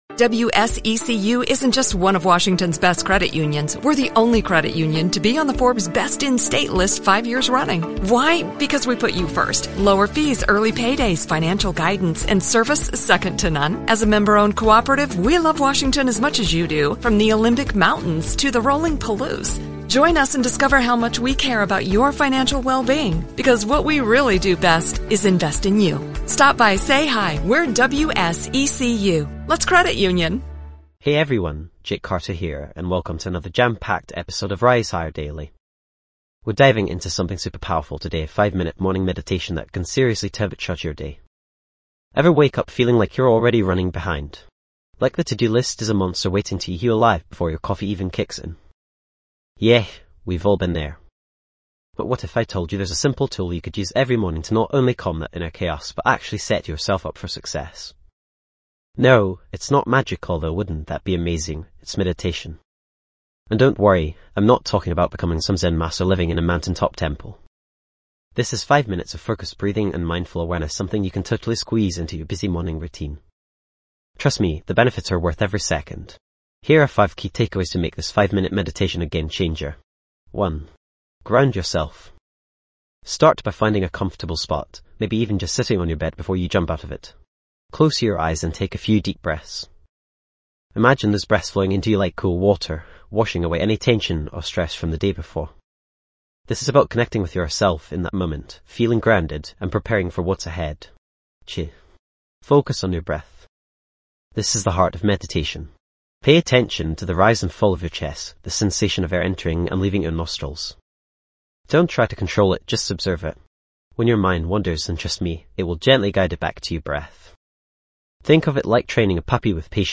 Episode Tags:. Morning meditation, Productivity tips, Meditation for success, Inner peace, Daily mindfulness practice
This podcast is created with the help of advanced AI to deliver thoughtful affirmations and positive messages just for you.